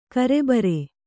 ♪ karebare